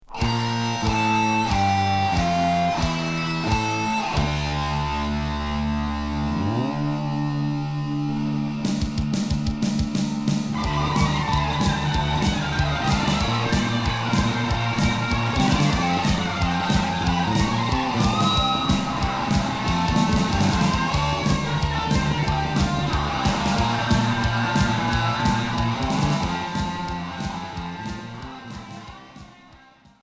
Hard heavy Unique 45t retour à l'accueil